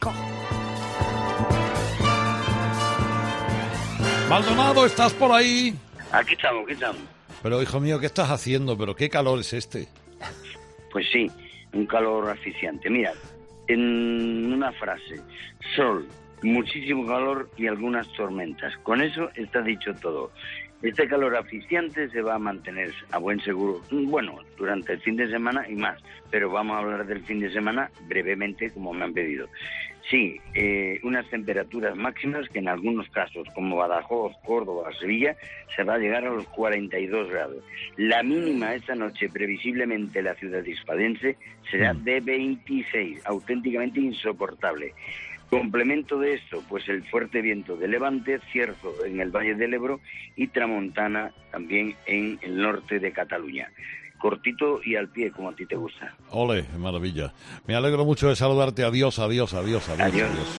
El tiempo con José Antonio Maldonado